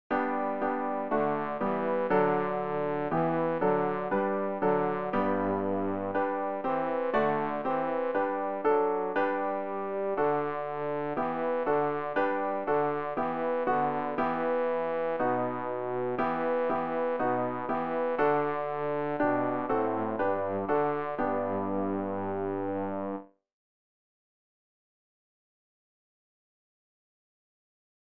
rg-815-jesu-leite-mich-bass.mp3